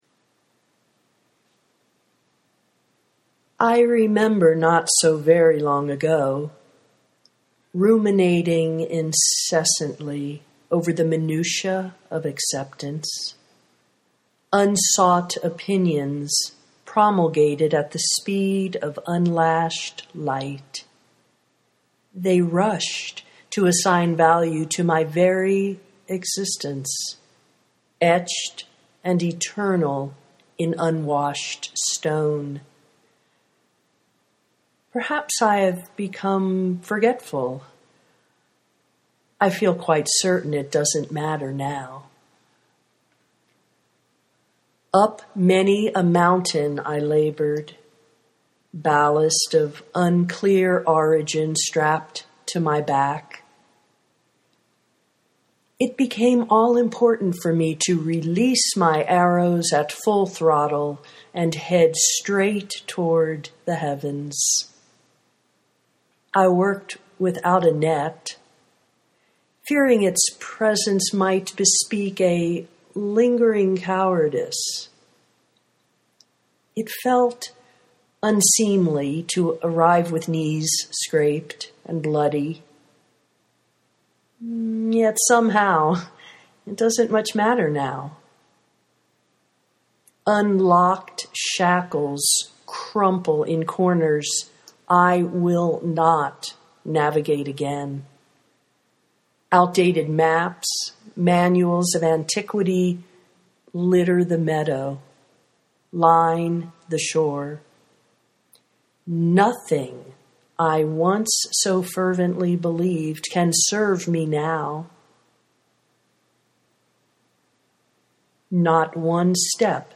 what doesn’t matter now (audio poetry 3:35)